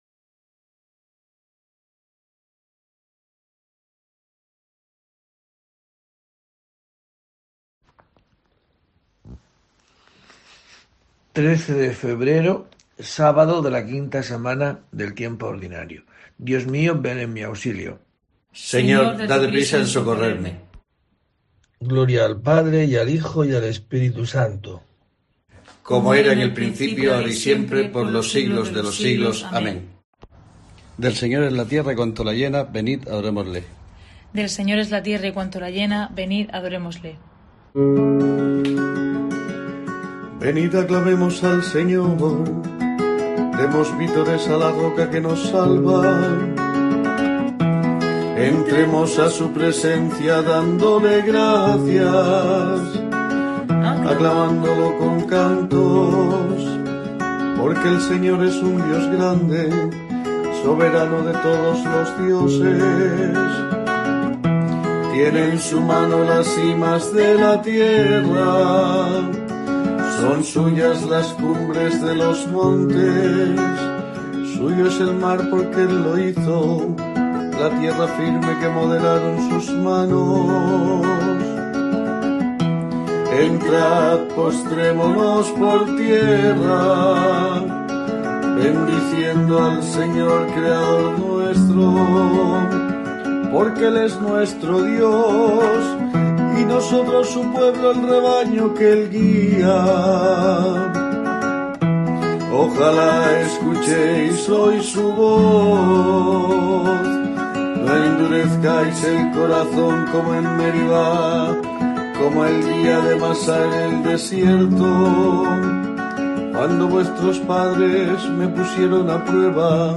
13 de febrero: COPE te trae el rezo diario de los Laudes para acompañarte